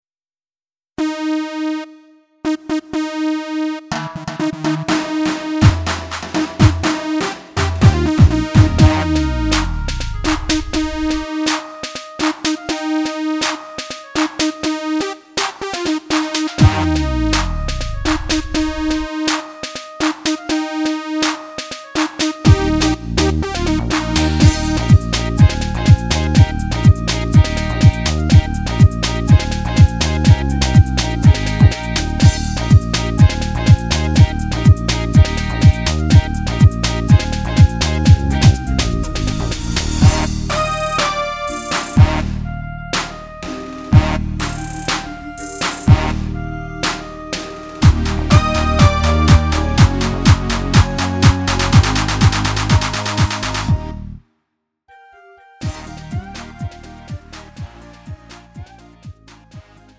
음정 -1키 장르 가요